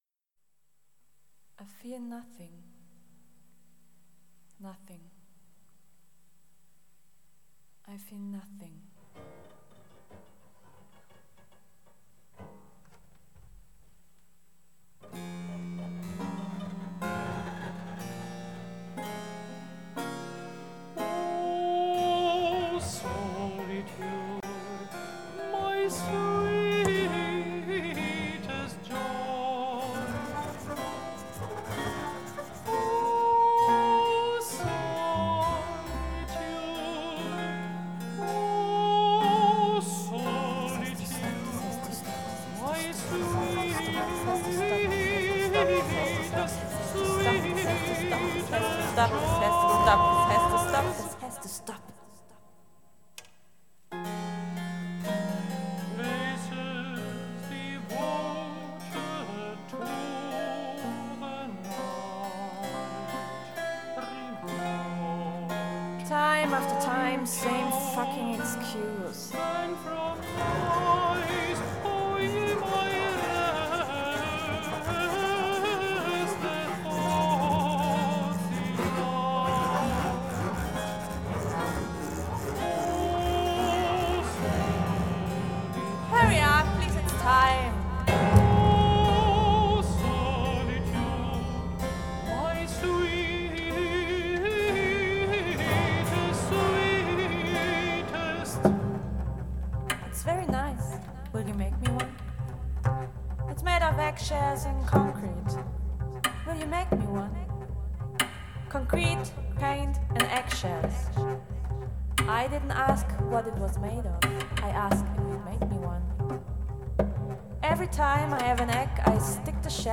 Ein Musikprojekt zwischen Barock und Jazz
Auschnitte aus Konzerten im Kulturforum Fürth, Oktober 2016